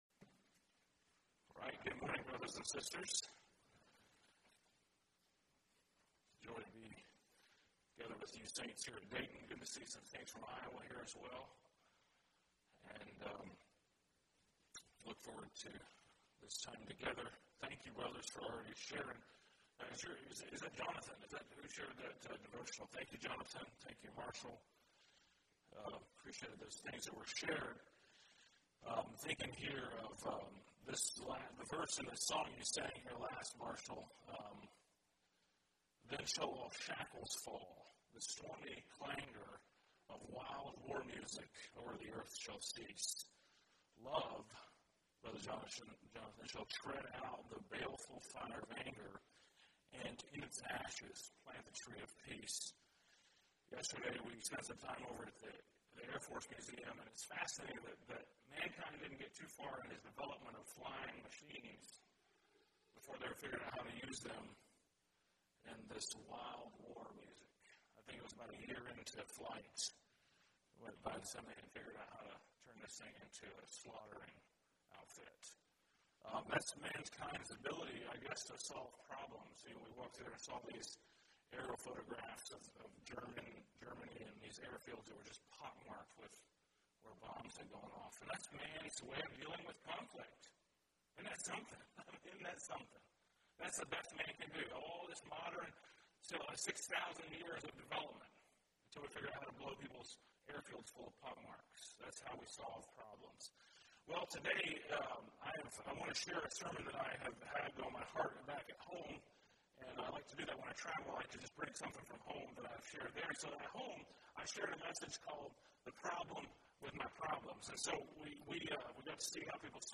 2024 Sermons - Dayton Christian Fellowship